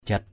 /ʥat/ (t.) thật, chính = vrai, pur. gruk jat g~K jT việc thật.